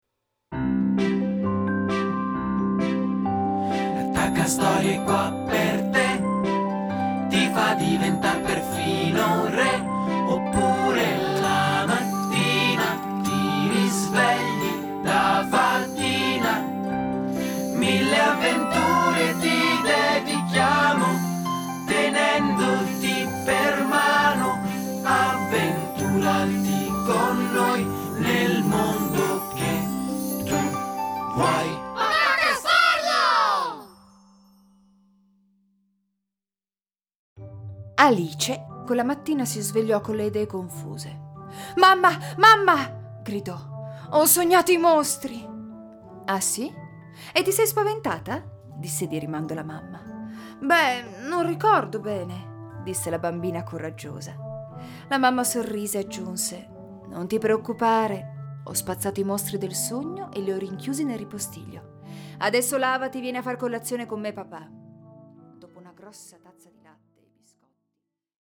Audiostoria personalizzata con il nome del tuo bambino o della tua bambina.
8 storie personalizzate con il nome del protagonsita, musicate e realizzate con le voci narranti di attori professionisti.